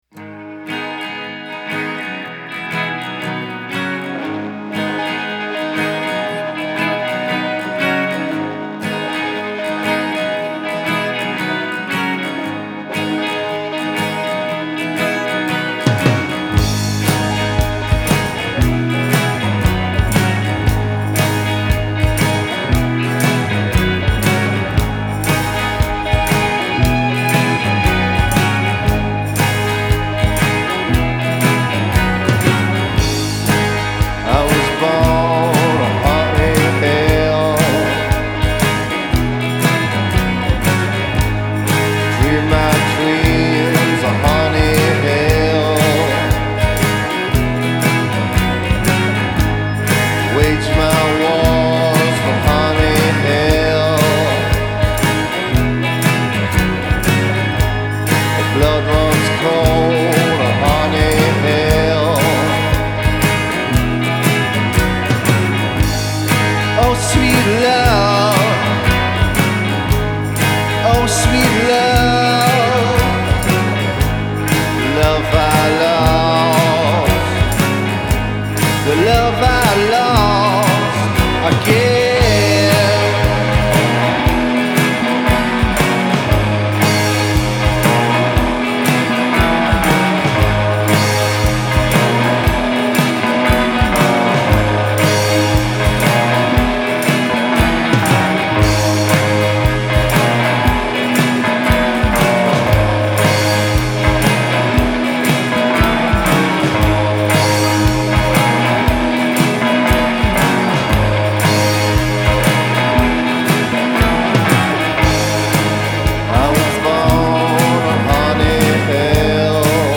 Genre: Indie/Psychedelic-Rock